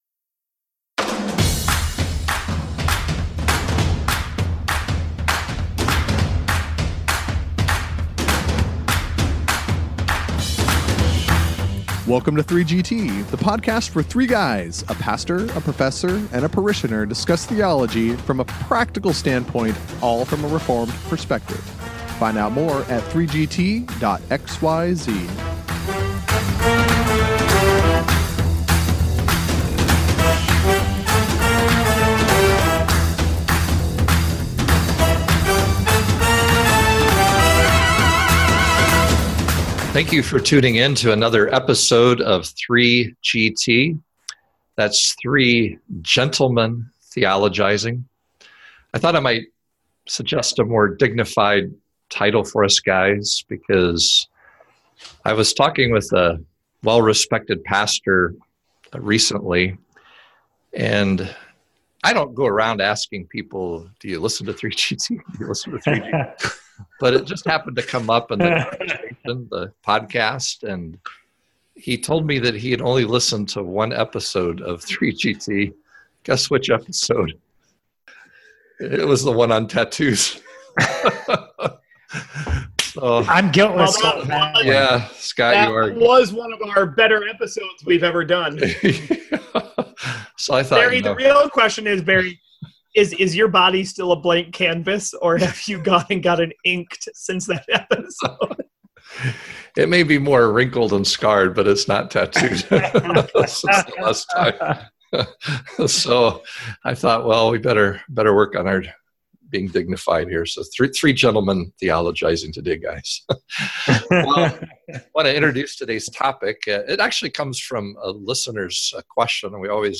The prof offers the basic definition of the word benediction. The pastor clarifies that not all Protestant worship services end this way. The parishioner gives some Biblical justification for the practice, then admits that the benediction to him is like that famous bell of Pavlov’s dog as he starts thinking about fellowship lunch. Along the way covenant theology, baptism, Catholicism, ordination, and even the doctrine of election play into the answer.